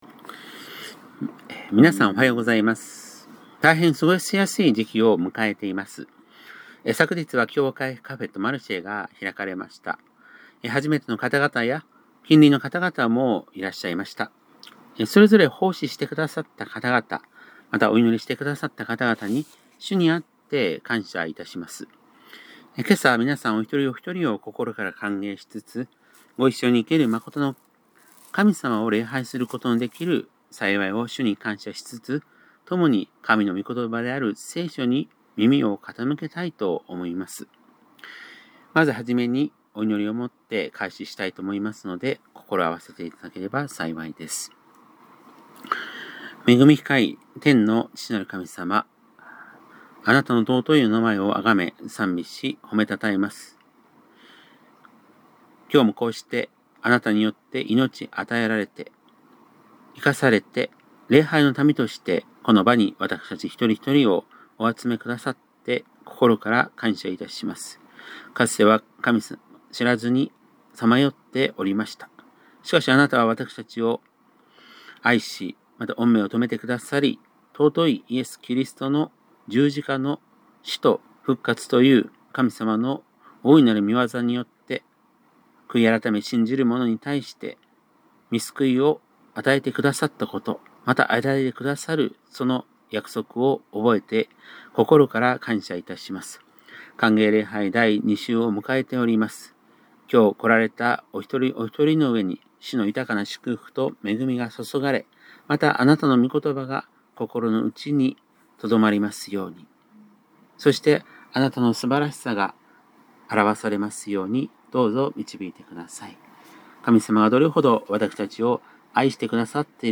2025年10月12日（日）礼拝メッセージ